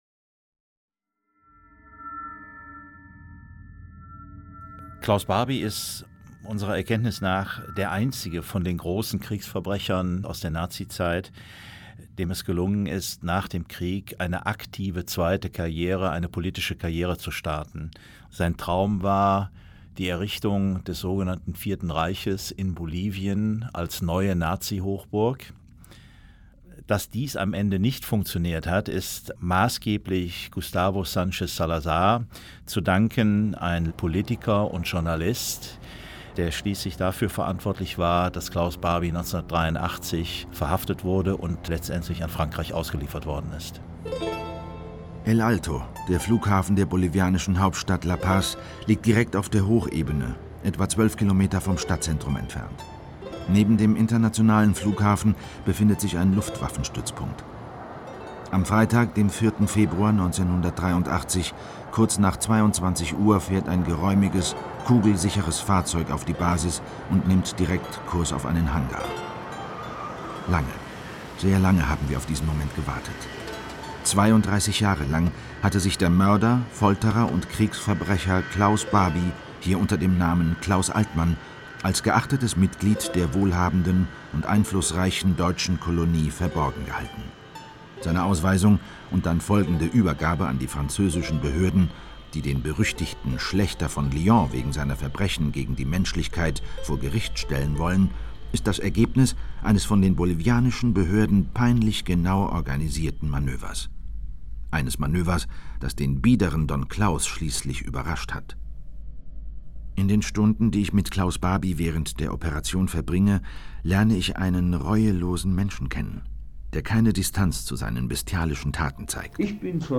Doku-Drama (2 CDs)
Felix von Manteuffel (Sprecher)
In bisher unveröffentlichten O-Ton-Aufnahmen plaudert der Massenmörder unbeschwert über seine Gräueltaten und seine erstaunliche Karriere nach der NS-Zeit.
Doku-Drama mit Felix von Manteuffel u.v.a.